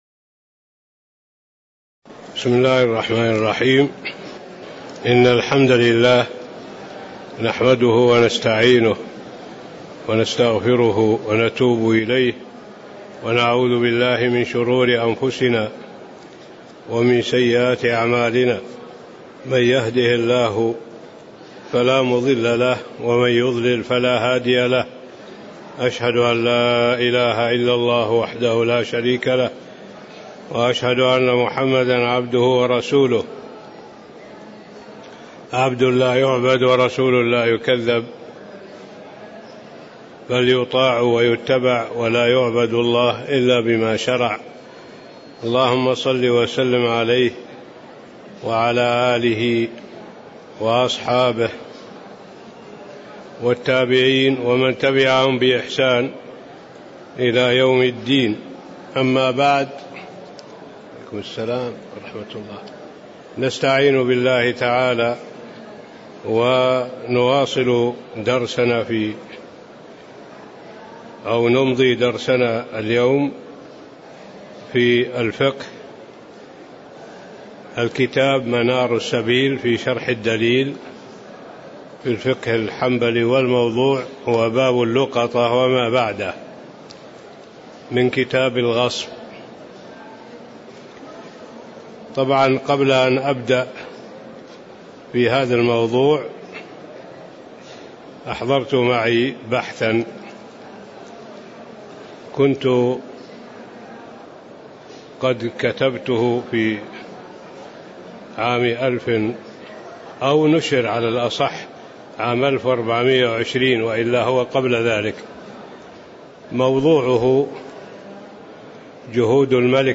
تاريخ النشر ٢٢ ربيع الأول ١٤٣٧ هـ المكان: المسجد النبوي الشيخ